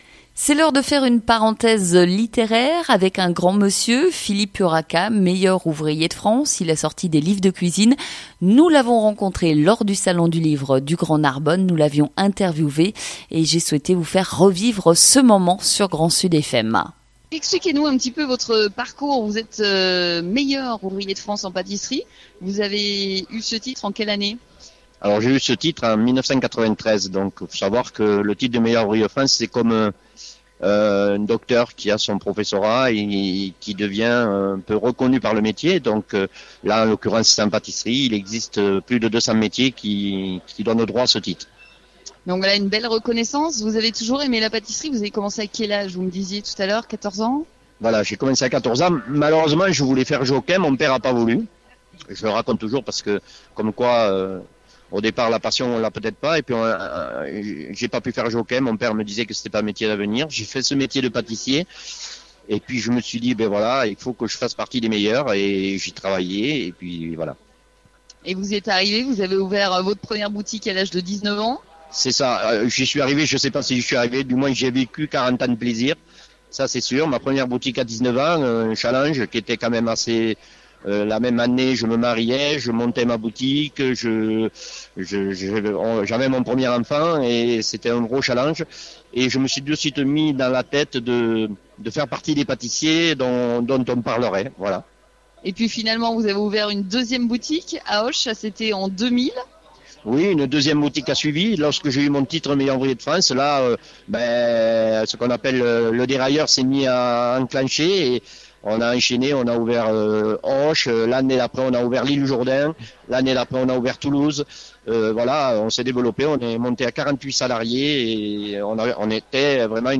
GRAND SUD FM en direct du cours Mirabeau à Narbonne